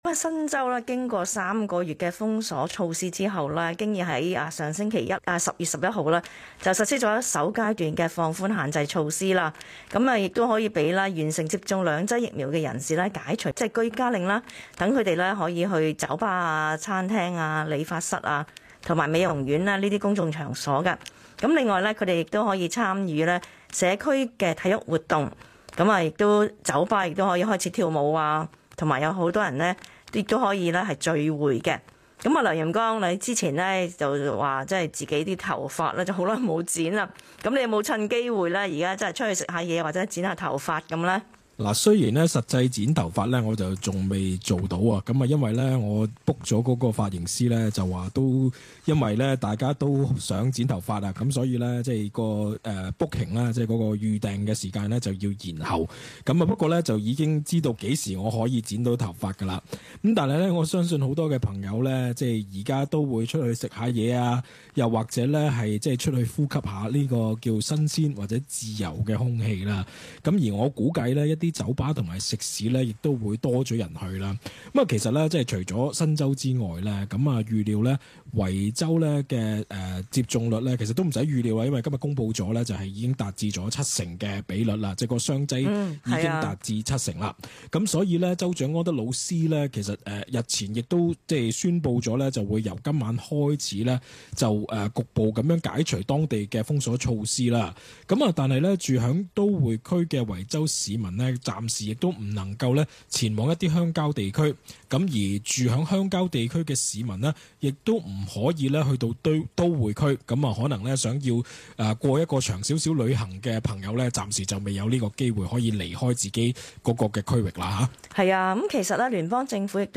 以上听众来电及讨论，并不代表本台立场。